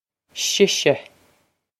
Sise she-shah
This is an approximate phonetic pronunciation of the phrase.